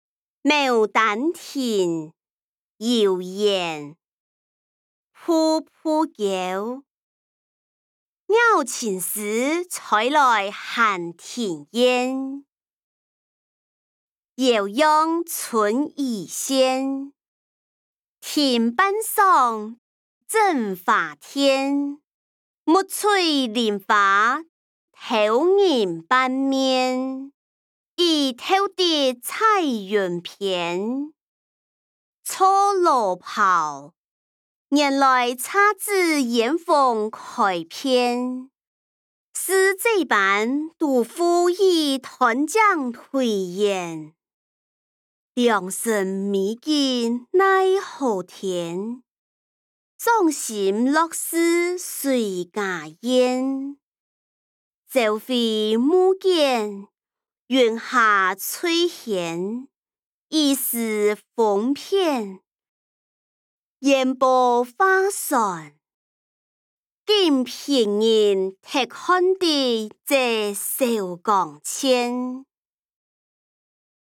詞、曲-牡丹亭．遊園音檔(四縣腔)